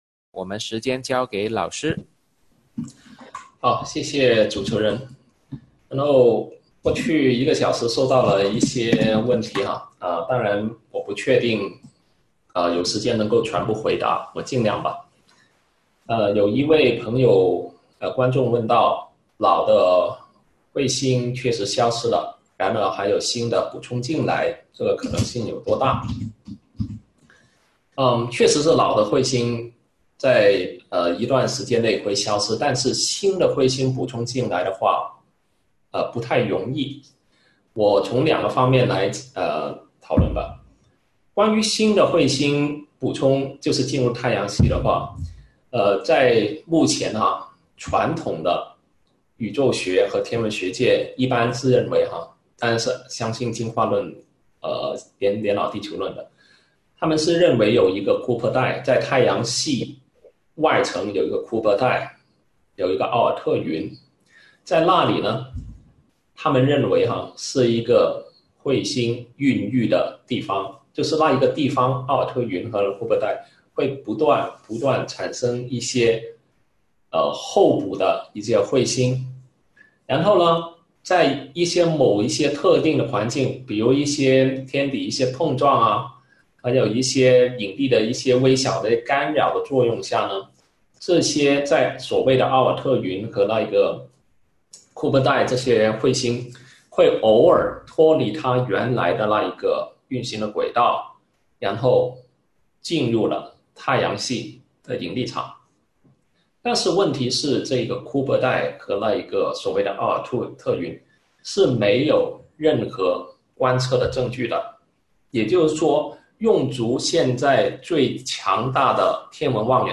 《年轻地球的证据》讲座直播回放